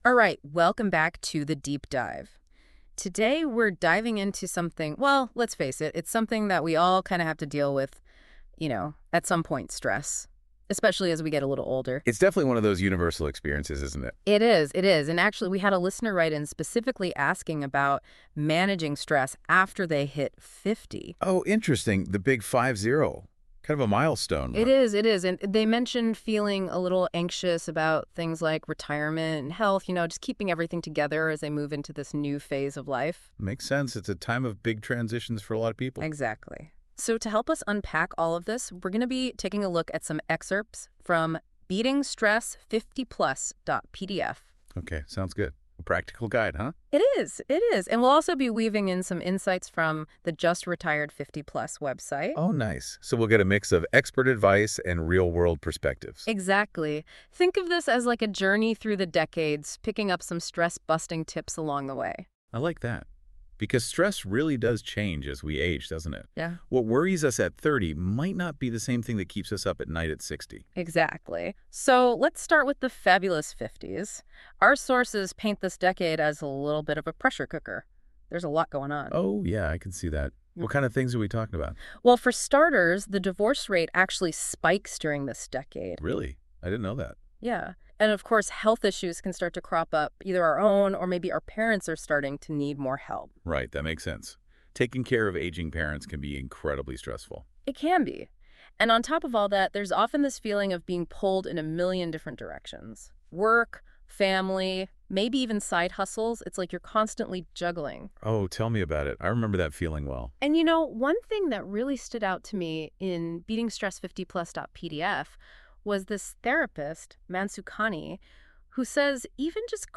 PodCast of this blog in conversation form available for easy listening ( Link from icon above ) How to manage stress after the age of 50 …………..